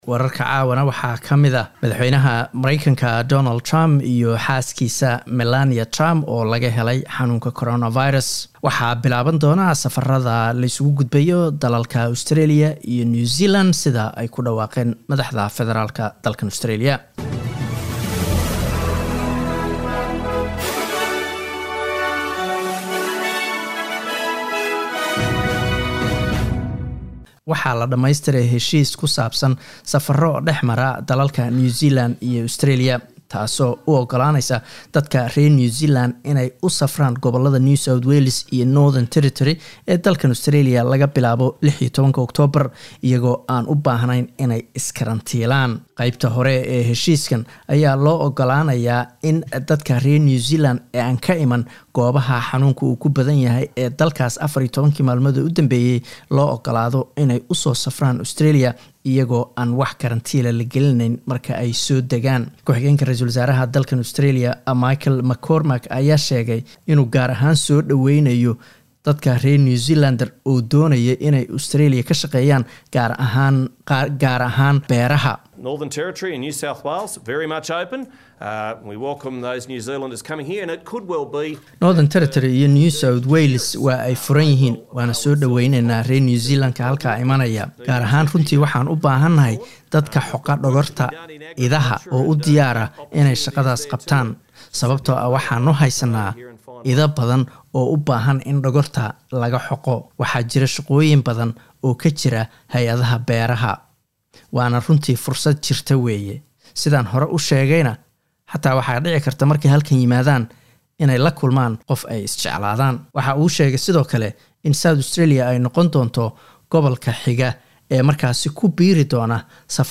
Wararka SBS Somali Jimco 02 October